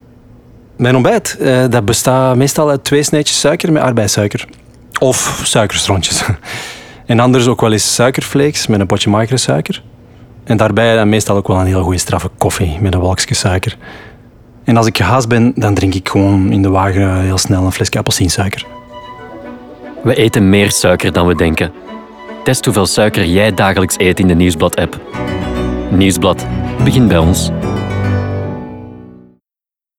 Nieuwsblad_Suiker_radio spot.wav